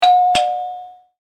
Door Bell 2; Typical Household Ding Dong Ring with Short Sustain In Release. Close Perspective.